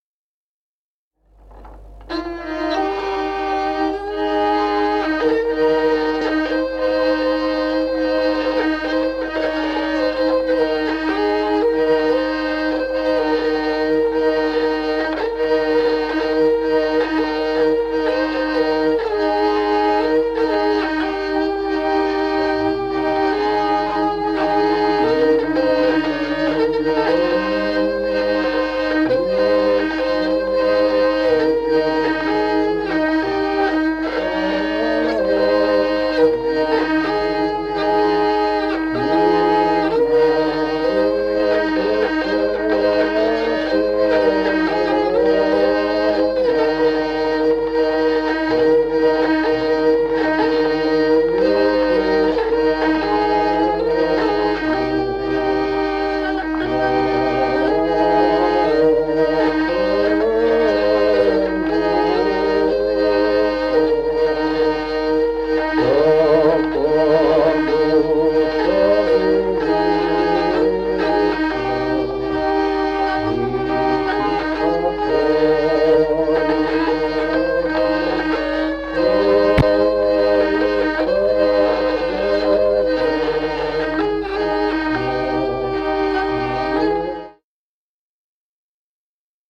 Музыкальный фольклор села Мишковка «От юности моея», партия лиры.